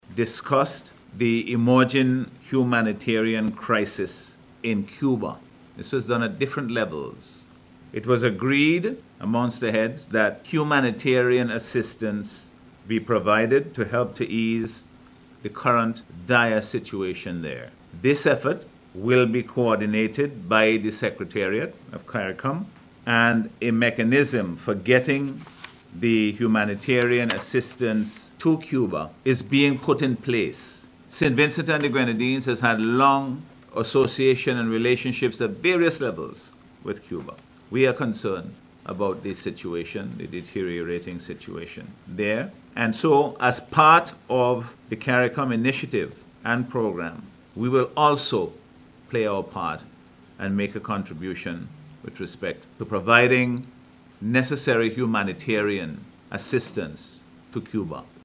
Prime Minister Dr. the Hon. Godwin Friday held a press conference earlier today to update the nation following the recent 50th Heads of Government Meeting in St. Kitts/Nevis